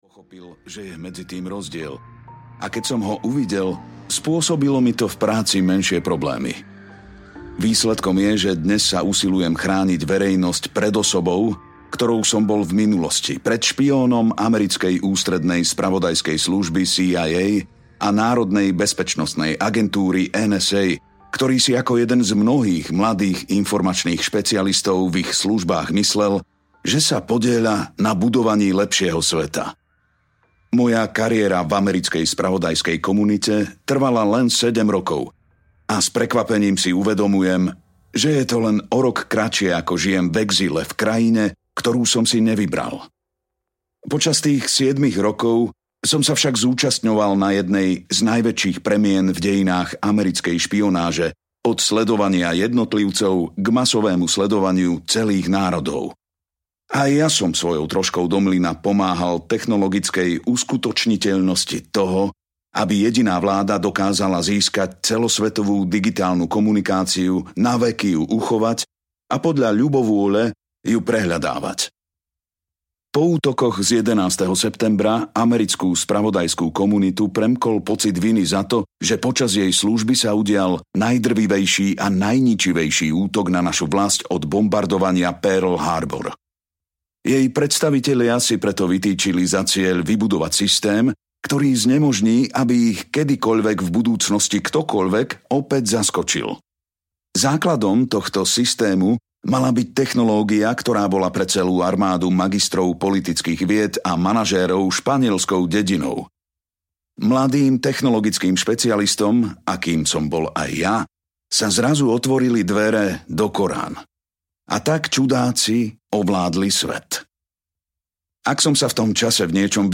Trvalý záznam audiokniha
Ukázka z knihy